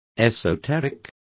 Complete with pronunciation of the translation of esoteric.